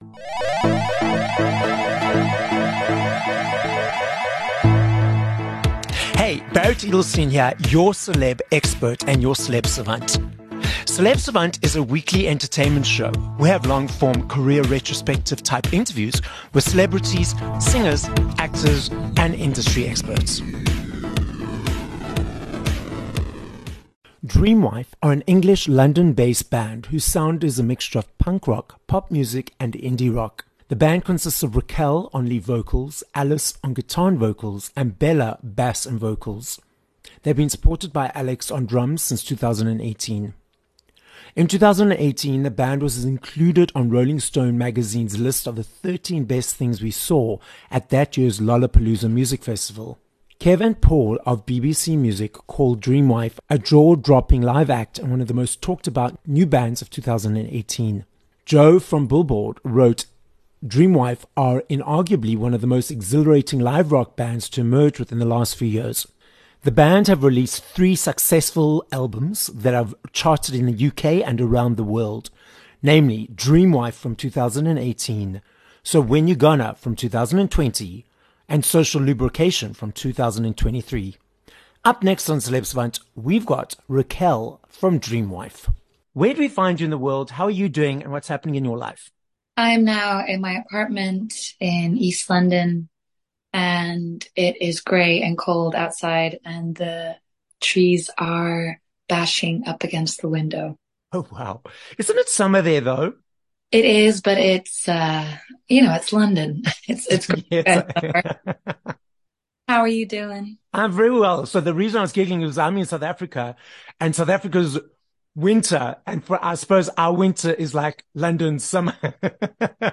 18 Jul Interview with Dream Wife